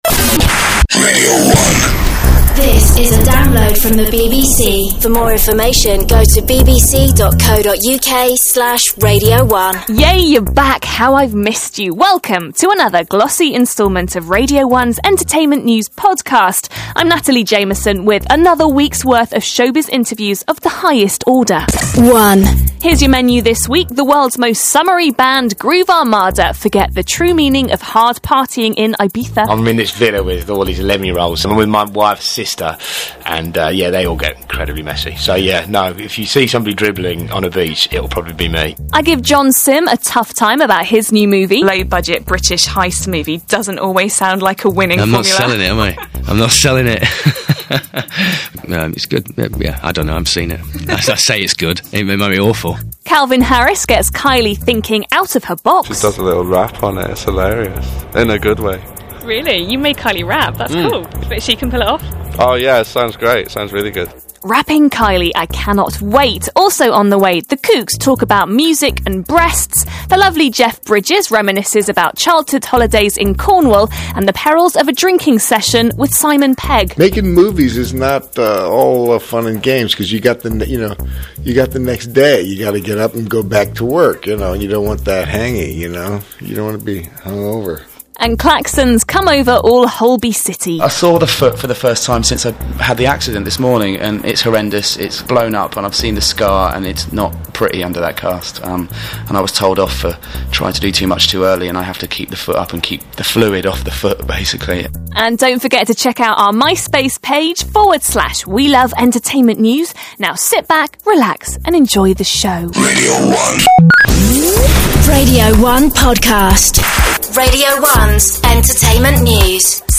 Interview: John Simm on Radio 1 Entertainment News Podcasts